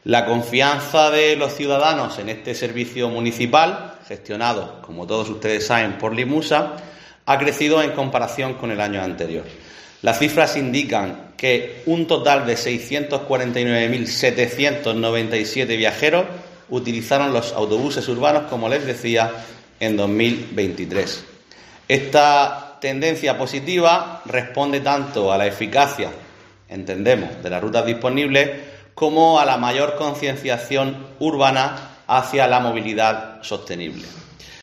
Juan Miguel Bayonas, concejal de Transportes del Ayuntamiento de Lorca